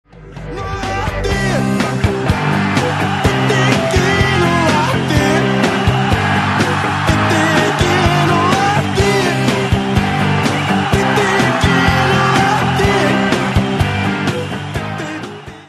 веселые
Alternative Rock
Pop Rock